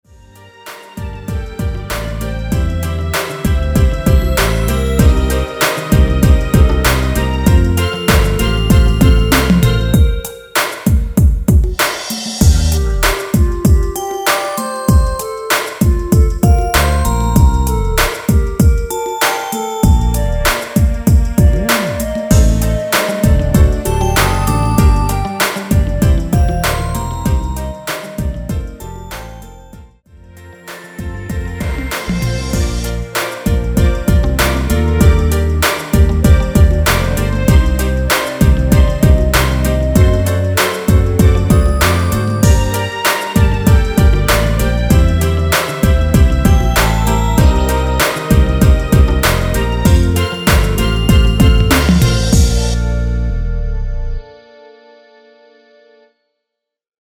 중간 간주 랩 없이 진행되고 마지막 랩 없이 끝납니다.(본문 가사 확인)
원키에서(-2)내린 랩부분 삭제 편곡한 멜로디 포함된 MR 입니다.(미리듣기 참조)
Bb
앞부분30초, 뒷부분30초씩 편집해서 올려 드리고 있습니다.
중간에 음이 끈어지고 다시 나오는 이유는